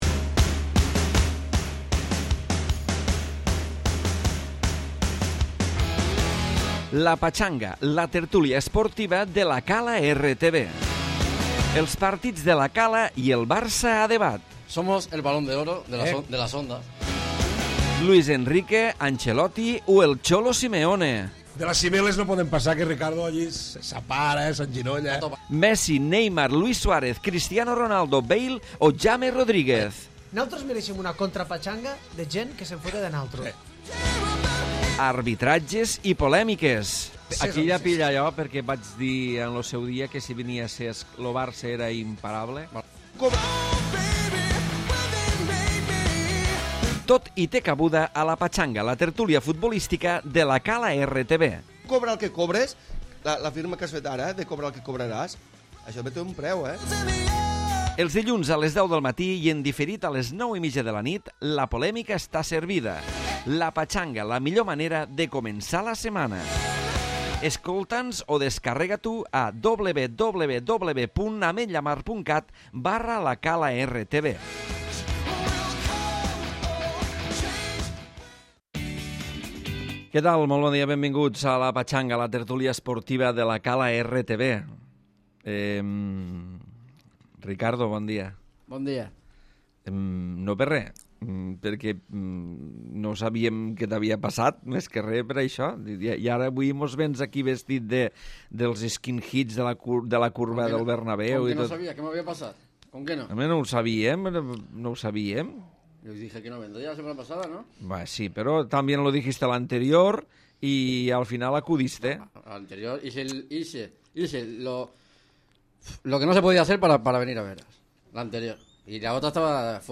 Tertúlia esportiva